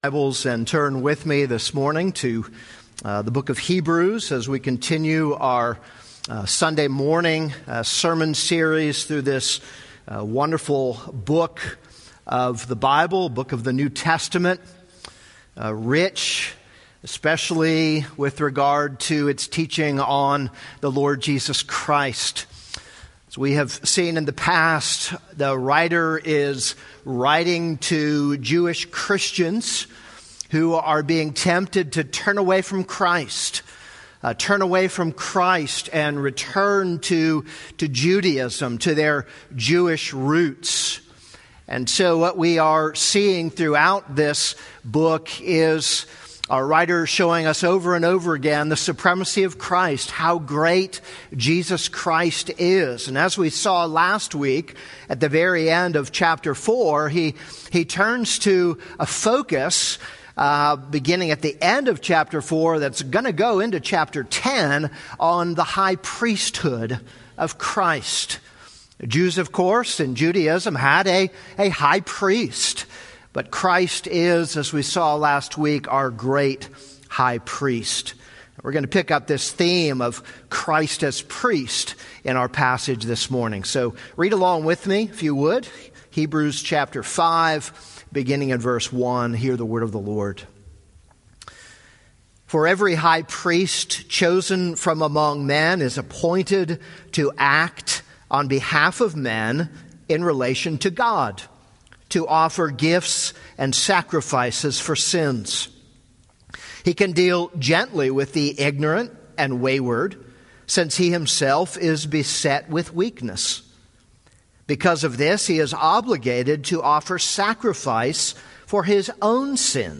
This is a sermon on Hebrews 5:1-10.